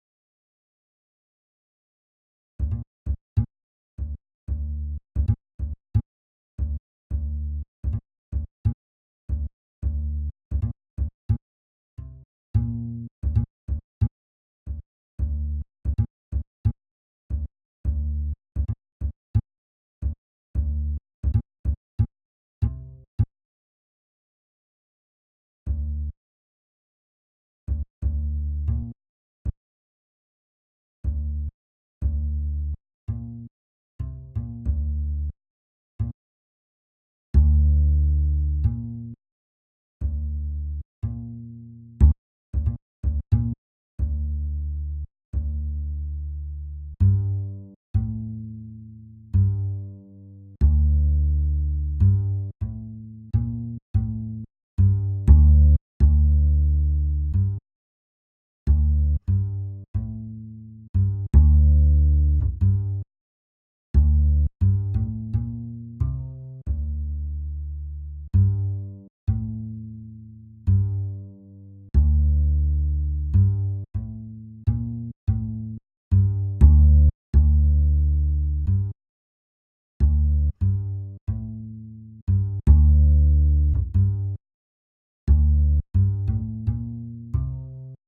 90 BPM